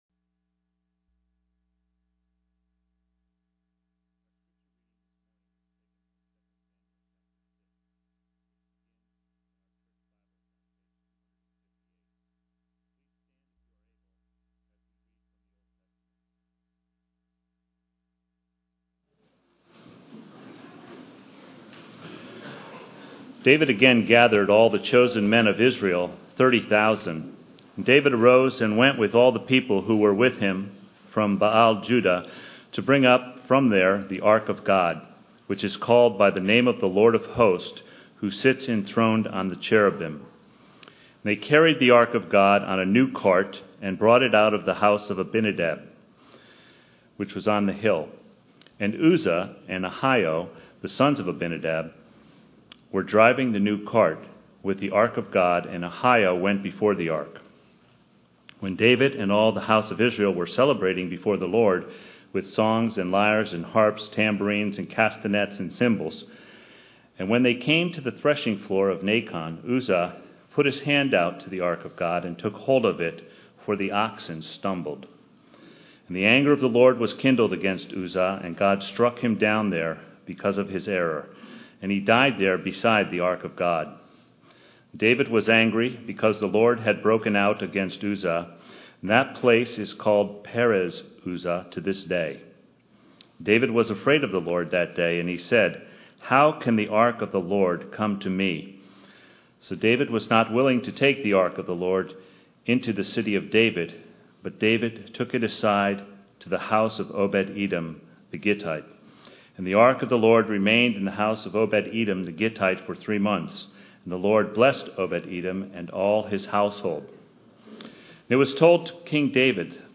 sermon-audio-6.2.13.mp3